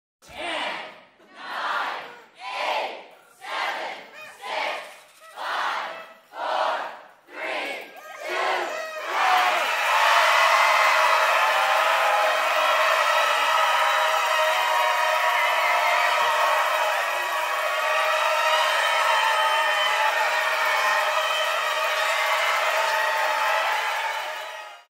Happy New Year Countdown Sound Effect Free Download
Happy New Year Countdown